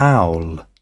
Transcription and pronunciation of the word "owl" in British and American variants.